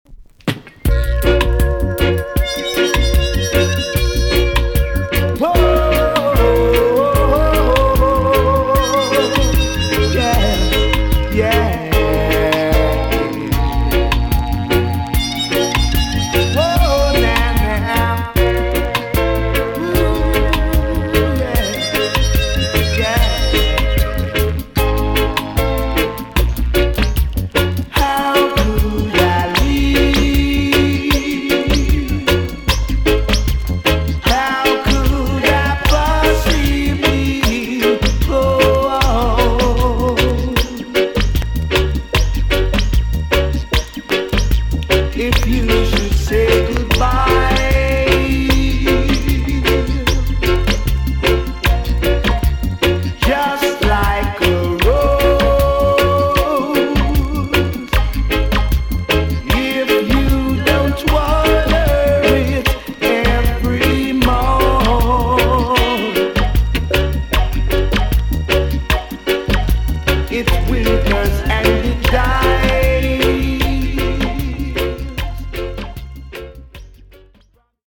TOP >DISCO45 >VINTAGE , OLDIES , REGGAE
EX- 音はキレイです。